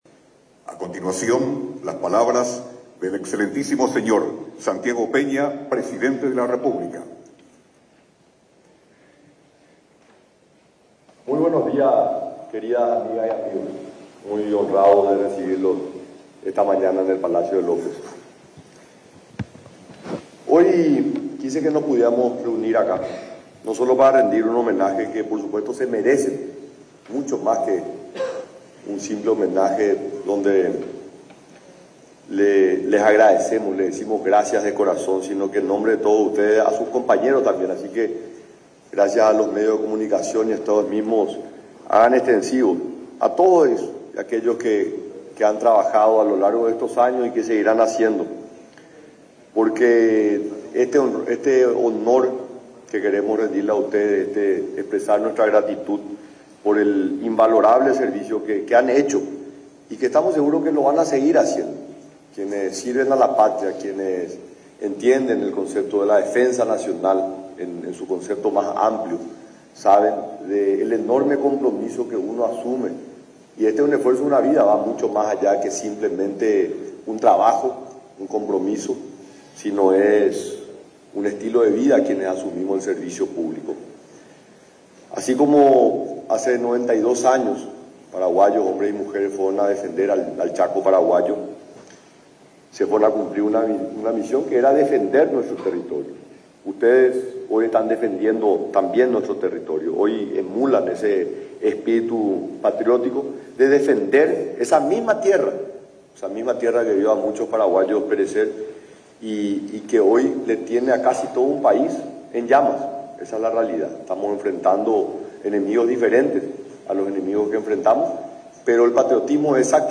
El presidente de la República, Santiago Peña, expresó el firme compromiso del Gobierno para combatir los incendios forestales, que se han venido repitiendo cíclicamente cada año. Comprometió más recursos y logística para los bomberos, durante un homenaje que dio a los voluntarios este jueves en el Palacio de López.